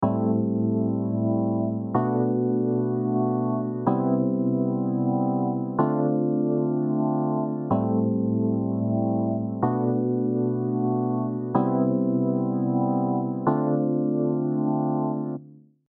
まずはドライの音。